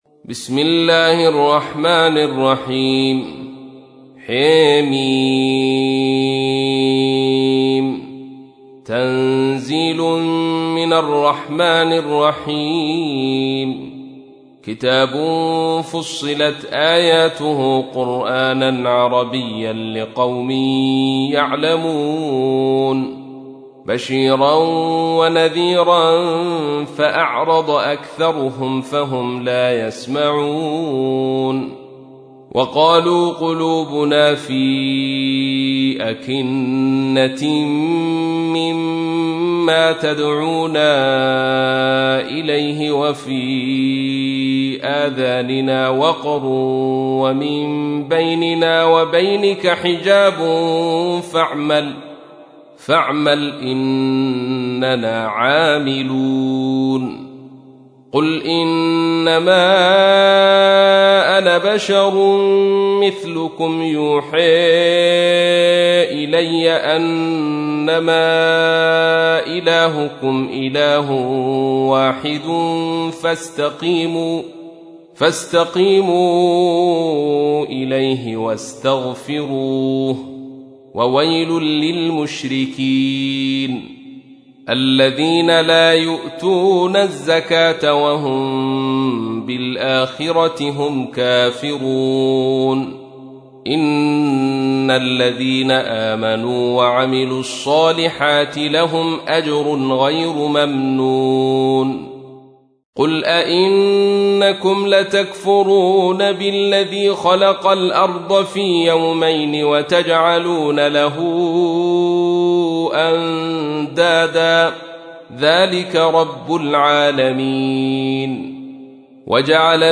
تحميل : 41. سورة فصلت / القارئ عبد الرشيد صوفي / القرآن الكريم / موقع يا حسين